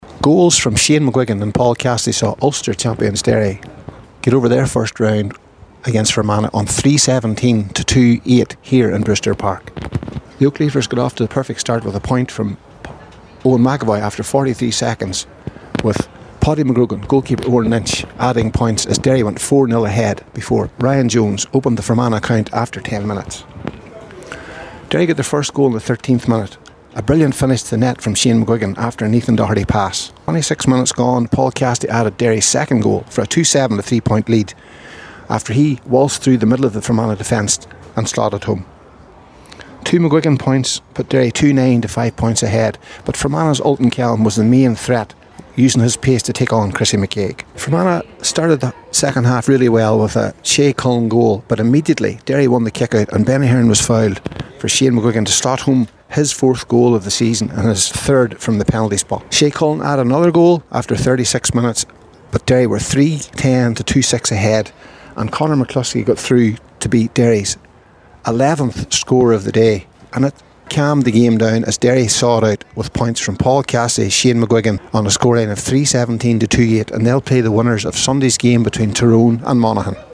the full time report…